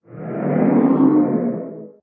guardian_idle1.ogg